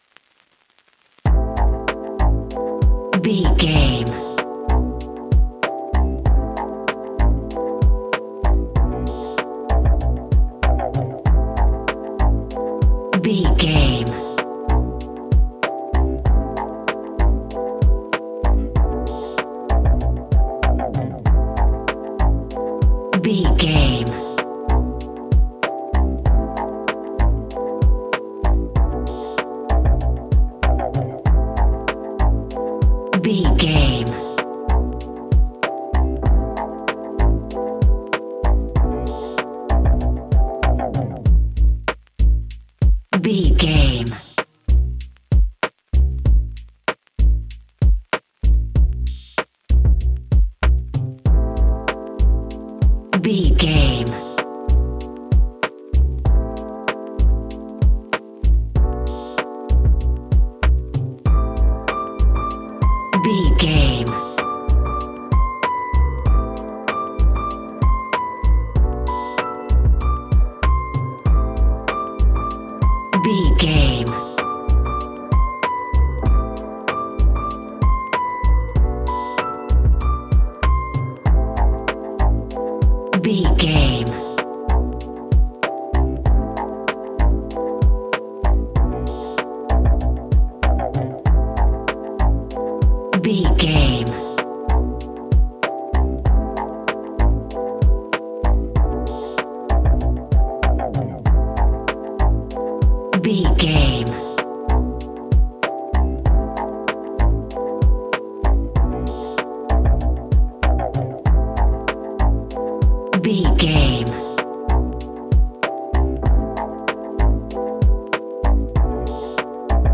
Ionian/Major
A♭
laid back
Lounge
sparse
new age
chilled electronica
ambient
atmospheric
morphing